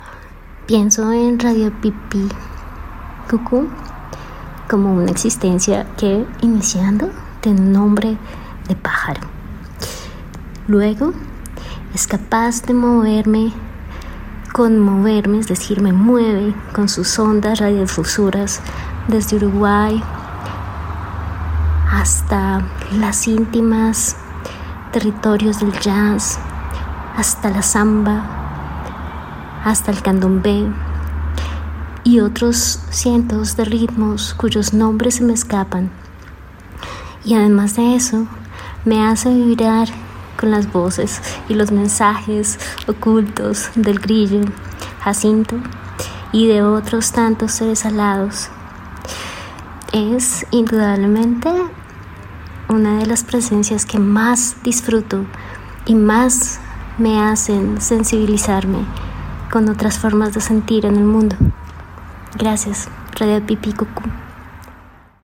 Pero ahora tenemos el primer audio real que nos enviaron desde Colombia